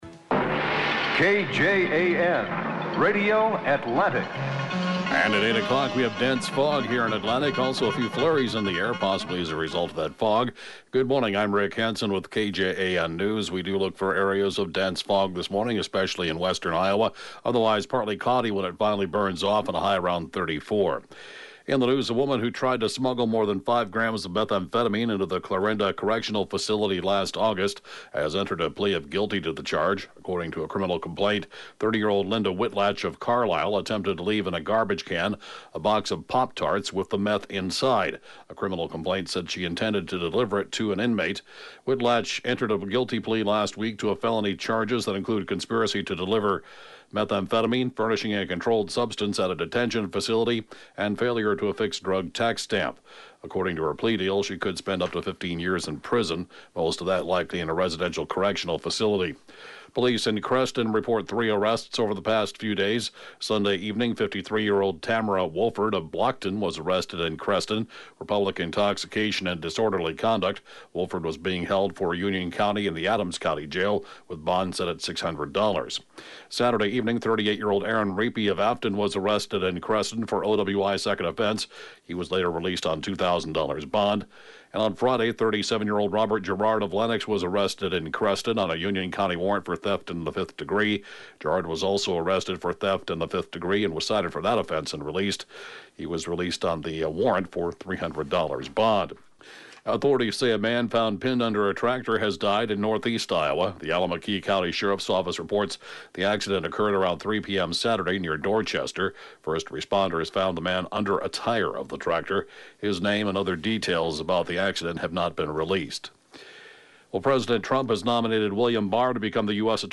(Podcast) KJAN 8-a.m. News, 1/9/2019